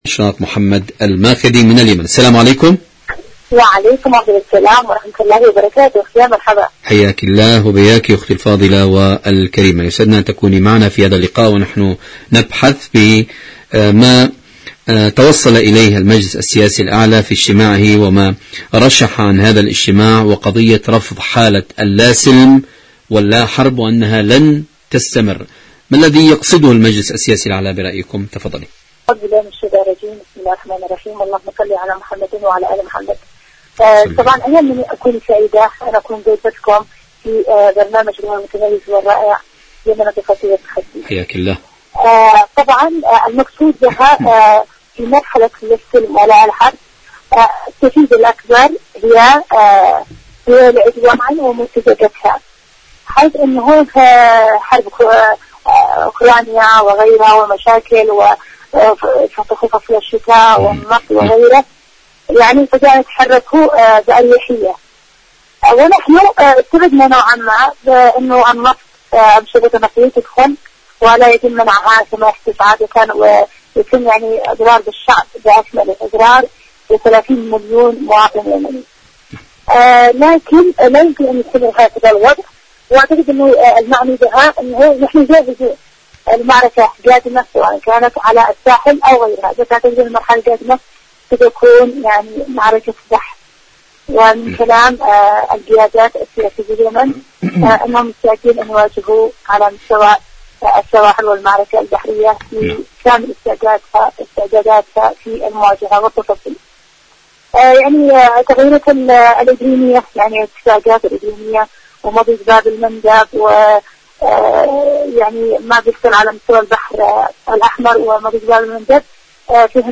مقابلات برامج إذاعة طهران العربية برنامج اليمن التصدي والتحدي اليمن مقابلات إذاعية العدوان على اليمن الشعب اليمني حركة أنصار الله اللاحرب اللاسلم الرفض شاركوا هذا الخبر مع أصدقائكم ذات صلة عاشوراء أيقونة الثوار..